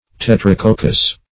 Search Result for " tetracoccous" : The Collaborative International Dictionary of English v.0.48: Tetracoccous \Tet`ra*coc"cous\, a. [See Tetra- , and Coccus .]